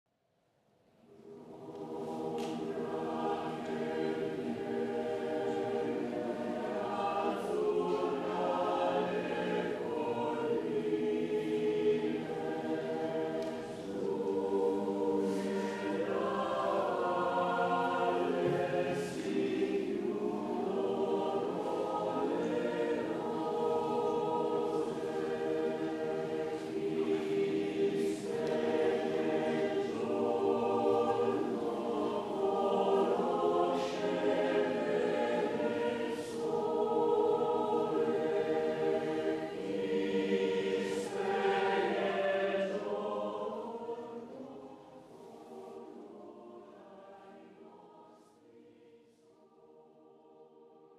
REPERTORIO DEL CORO ANA AVIANO